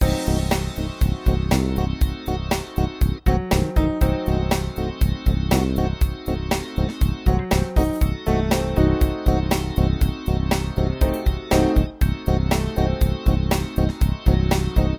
Diminished Mode